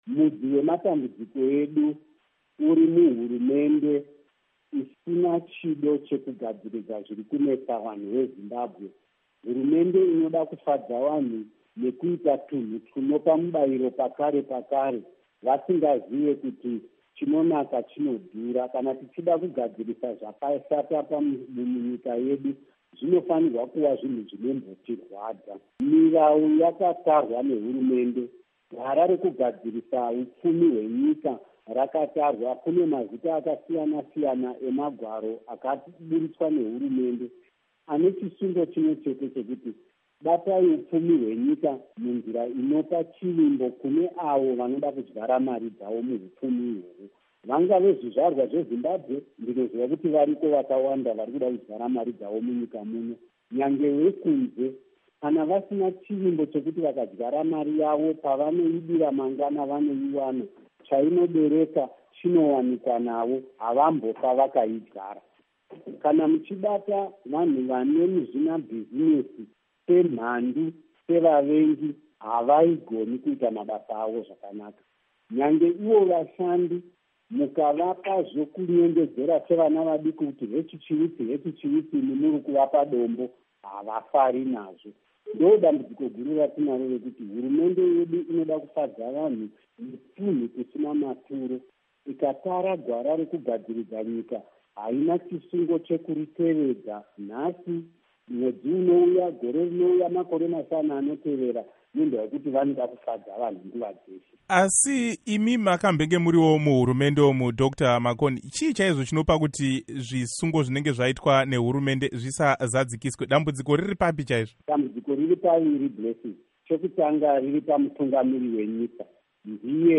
Hurukuro naVaSimba Makoni